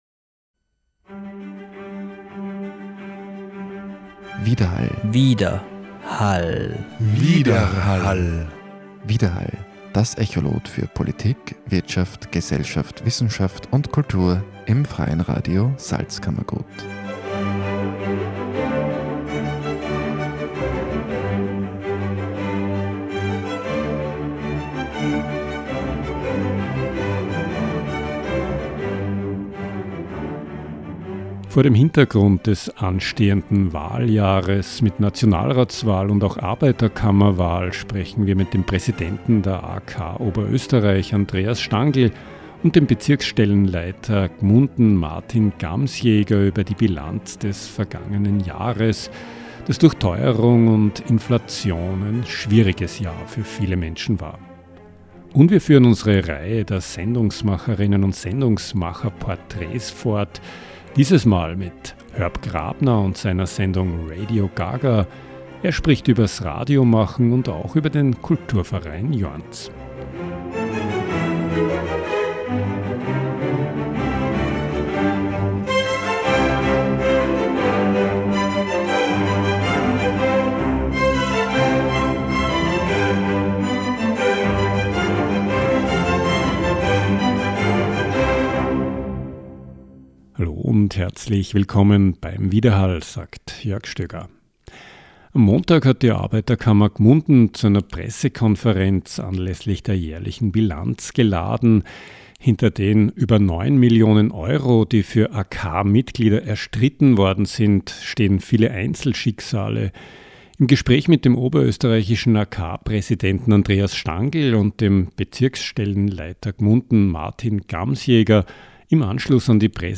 Im Gespräch mit dem oberösterreichischen AK-Präsidenten Andreas Stangl